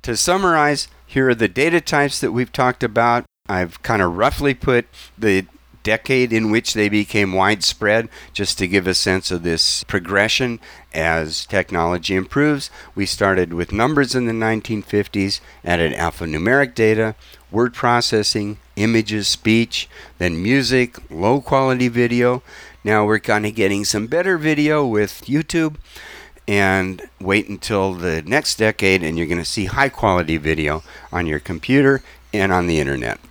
This is not professional quality cleanup -- it is quick and dirty, but is good enough for many applications.